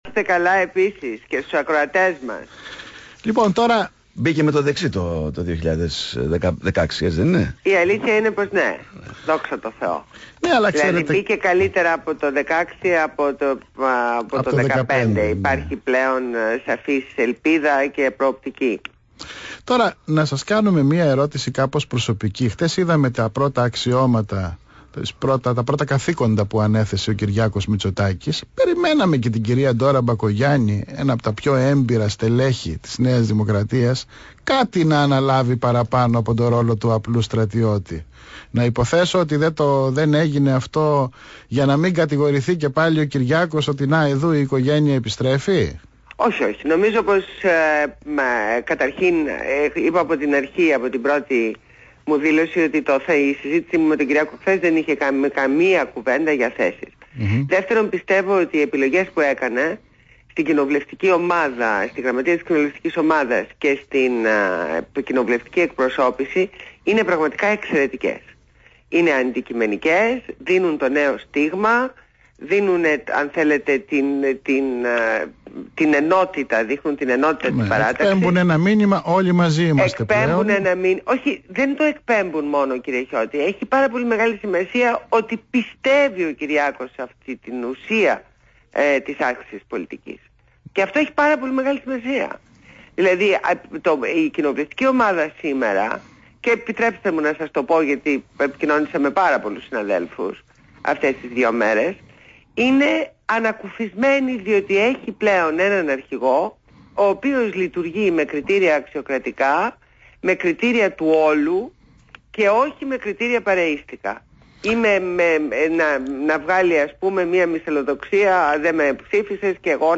Συνέντευξη στο ραδιόφωνο ΒΗΜΑfm